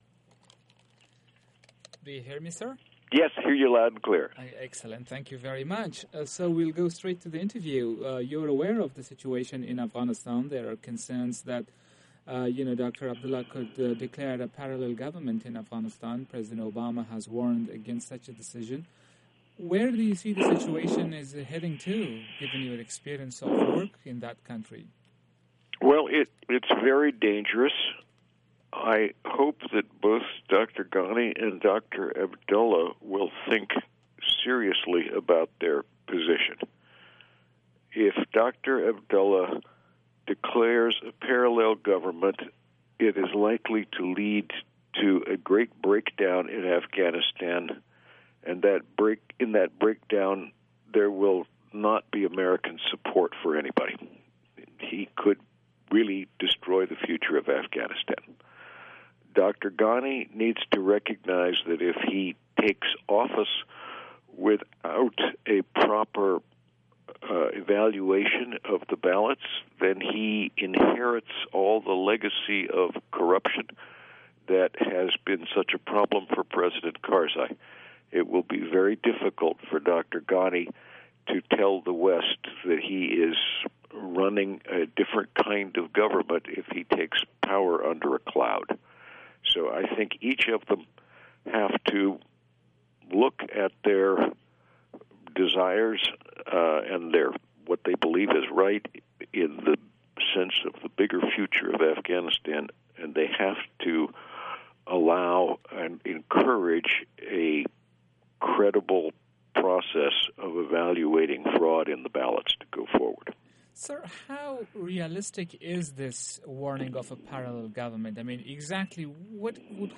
مصاحبه ها
مصاحبه اختصاصی رادیو آشنای صدای امریکا با رانالد نیومن سفیر سابق ایالات متحده به افغانستان